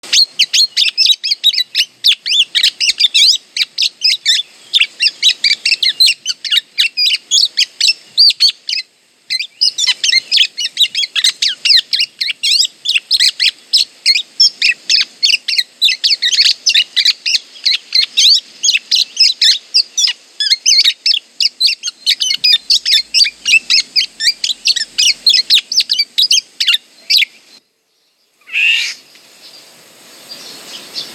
• Cântă dimineața devreme, cu un glas atât de clar și frumos încât pare ireal.
Ascultă-i cântecul perfect din zori!
Grangur.m4a